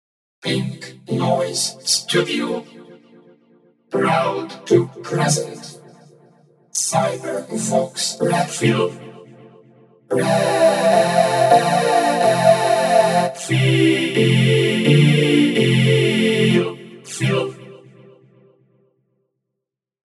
Played and recorded live, there was no additional editing, so these demos show you how Cybervox will sound when you play on a midi keyboard - live.
CV_playing_VPK-1.mp3